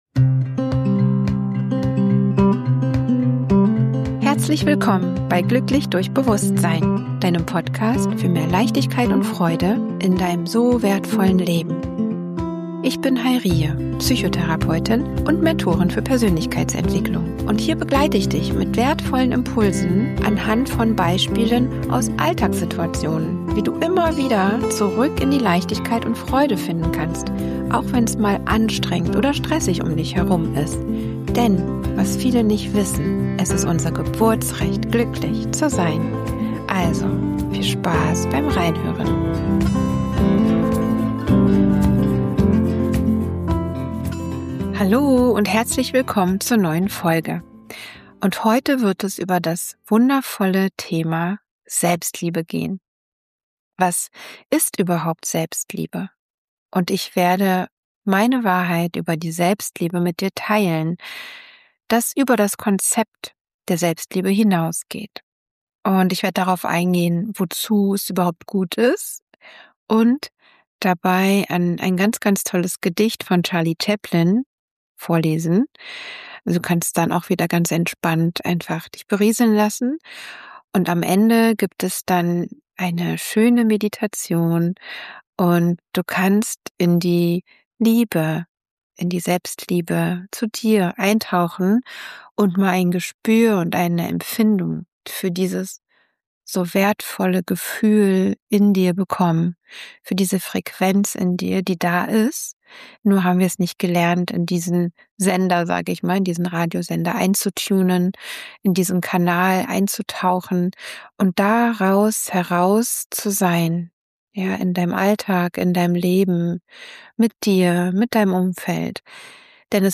Am Ende erwartet dich eine geführte Meditation, in der du Selbstliebe nicht nur verstehst, sondern fühlst.